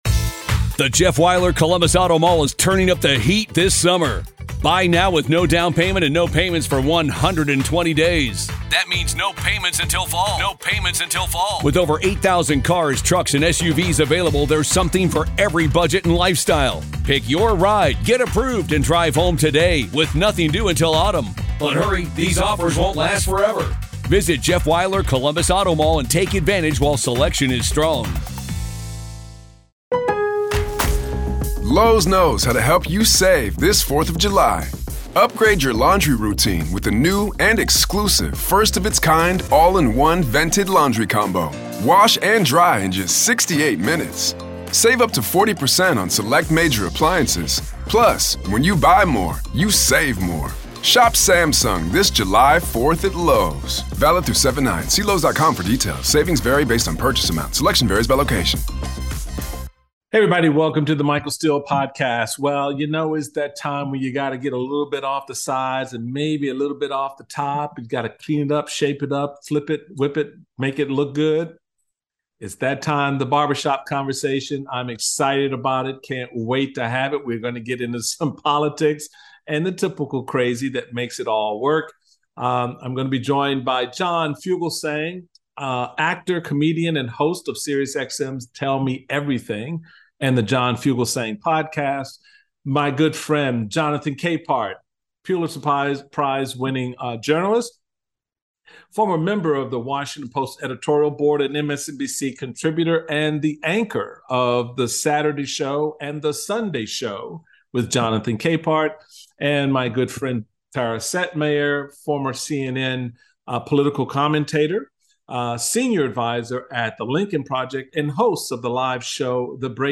Michael is joined by Jonathan Capehart, John Fugelsang and Tara Setmayer for a conversation on all kinds of politics and crazy, touching on Ronna McDaniel's "loyalty oath," McCarthy's gift to Tucker "Tuckems" Carlson of January 6th footage, the Fox News-Dominion lawsuit and of course, 2024. Who will run if not Biden and why are Democrats so against voting him in a second term? Plus, will the GOP succeed in diversifying their base with candidates like Tim Scott and Nikki Haley?